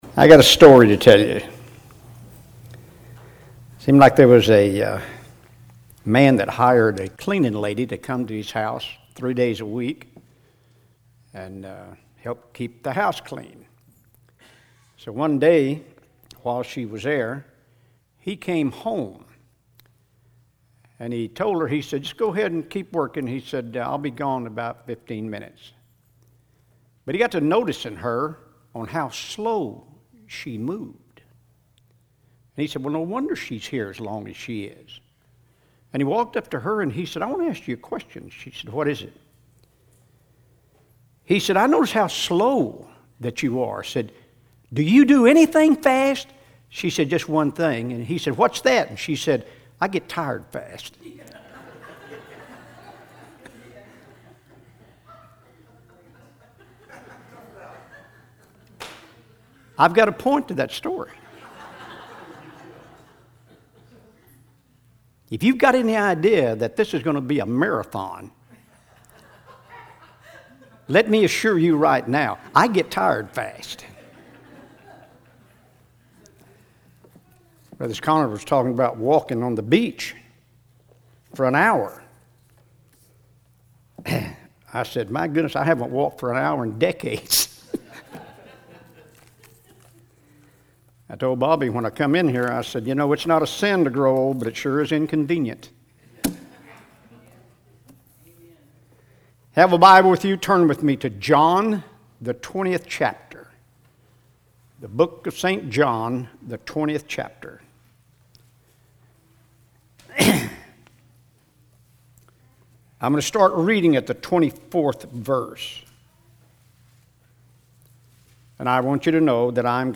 Special Sermons